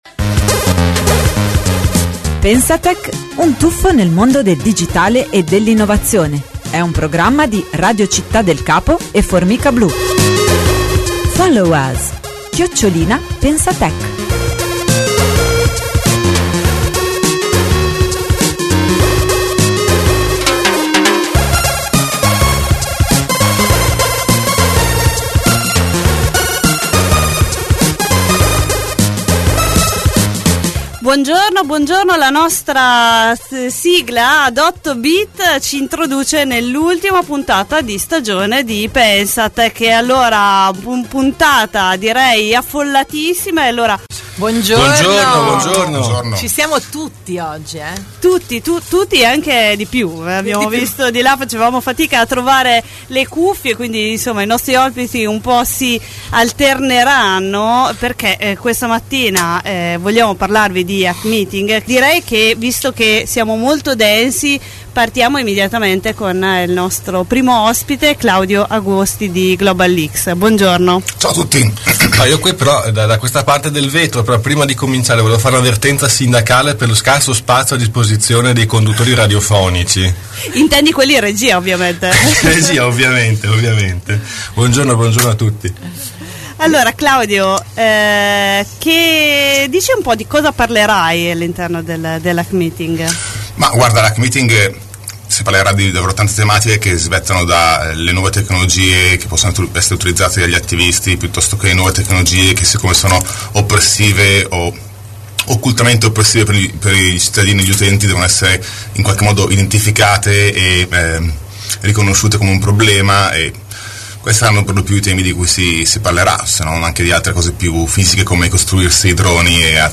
Ascolta tutta la trasmissione 2014-06-27-pensatech Download